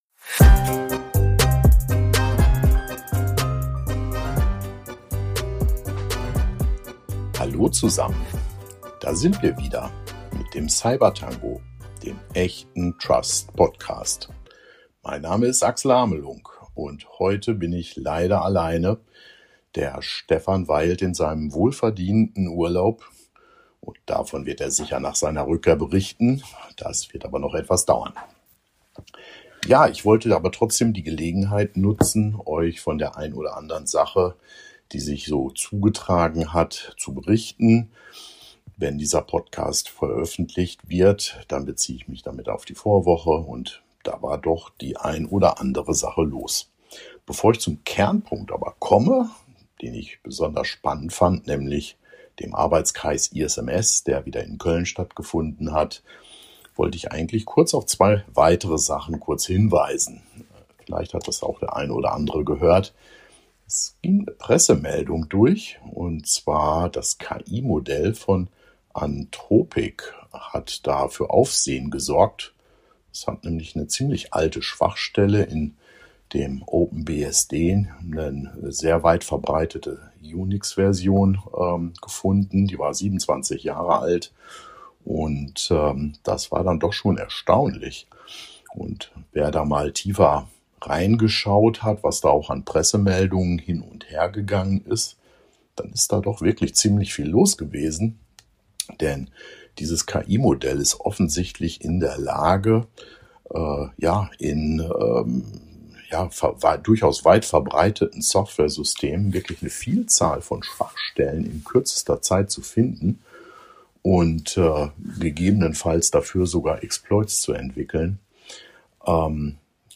Das bedeutet: kein gewohntes Zwiegespräch, sondern ein waschechter Monolog, fast schon ein kleines Hörbuch.